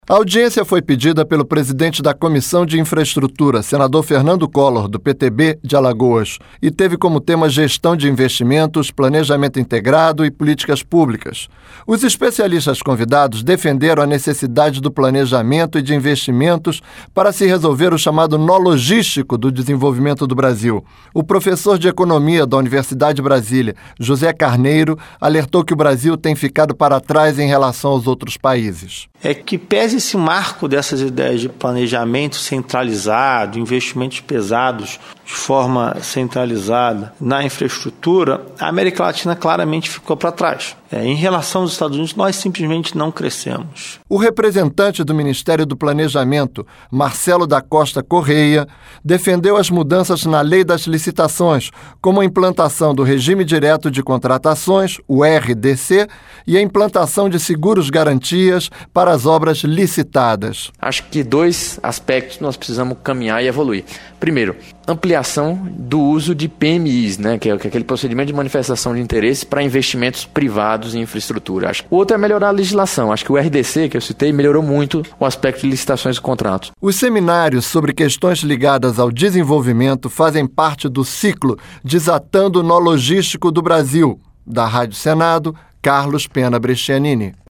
Da Rádio Senado